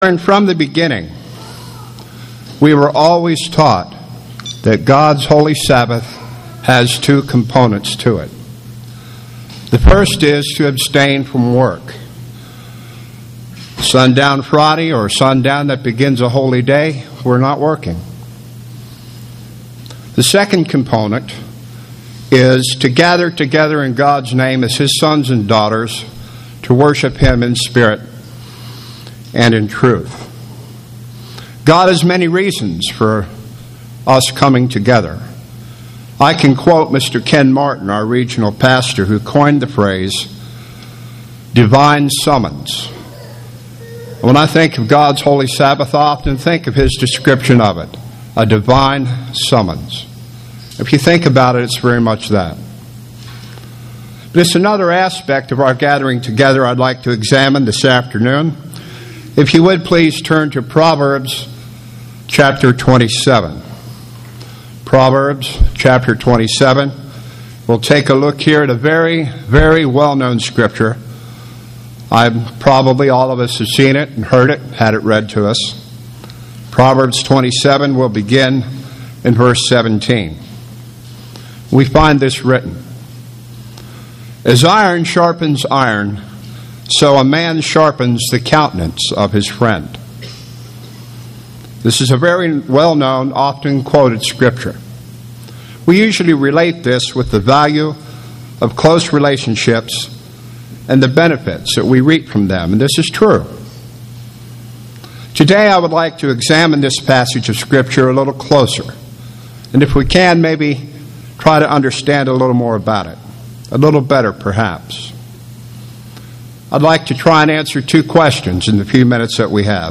Given in Atlanta, GA
UCG Sermon words Studying the bible?